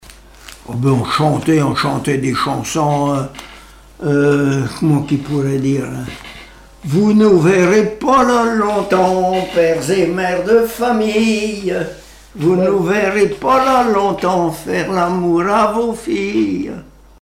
Mémoires et Patrimoines vivants - RaddO est une base de données d'archives iconographiques et sonores.
Chants brefs - Conscription
Fonction d'après l'analyste gestuel : à marcher
Catégorie Pièce musicale inédite